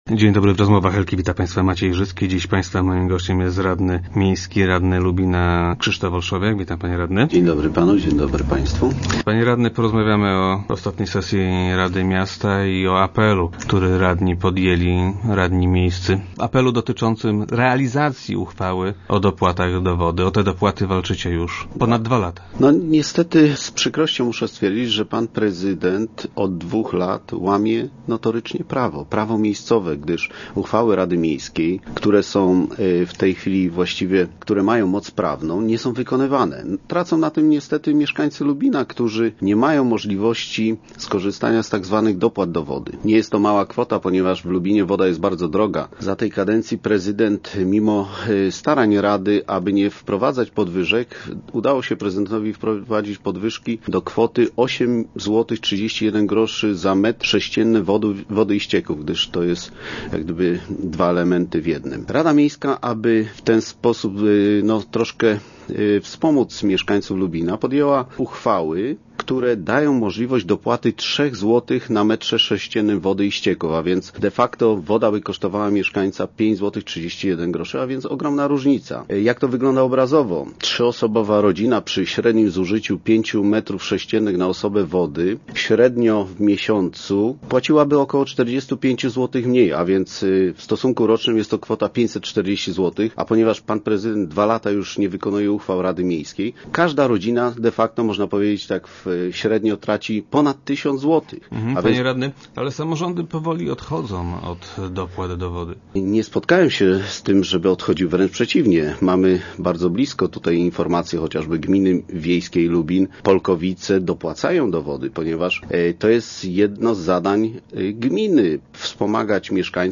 - Prezydent notorycznie łamie prawo - twierdzi Krzysztof Olszowiak, radny PO, który był gościem piątkowych Rozmów Elki.